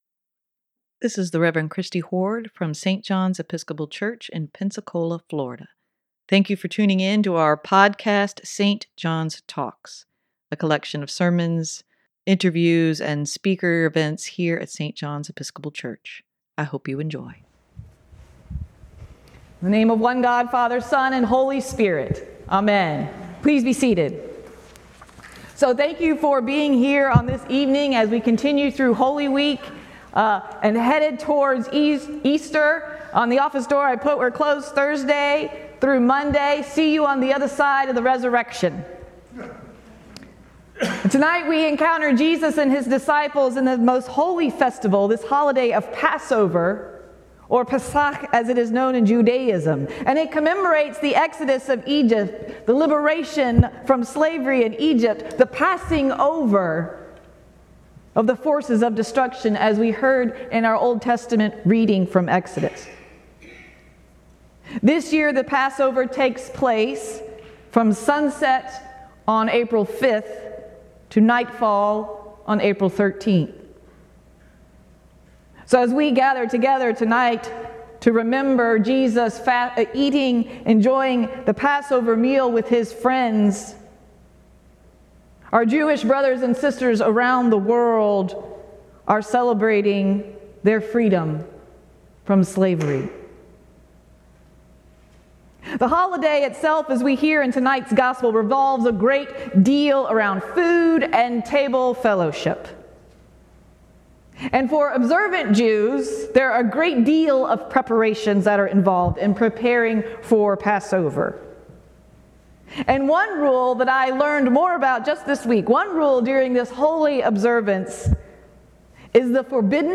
Homily for Maundy Thursday evening service
Homily-Maundy-Thursday2023.mp3